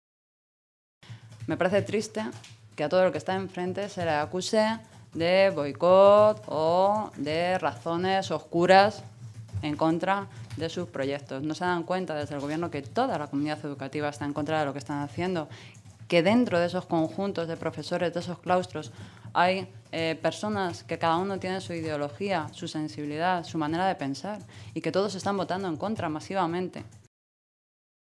Carmen Rodrigo, portavoz de Educación del Grupo Parlamentario Socialista
Cortes de audio de la rueda de prensa